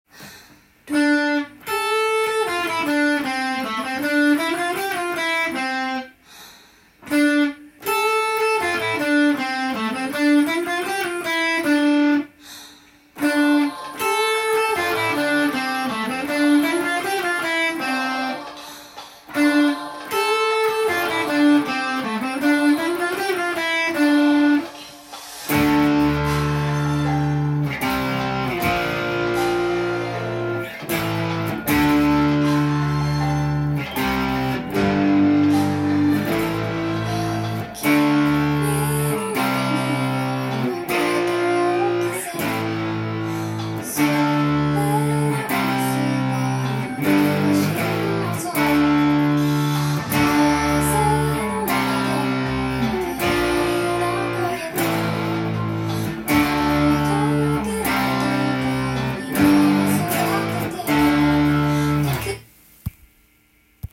曲のほう聞いてみると神秘的で素晴らしい曲です。
音源に合わせて譜面通り弾いてみました
女性ボーカルが透き通った神秘的な雰囲気を醸し出しています。
ギターパートの方はイントロが　ボーカルとユニゾンする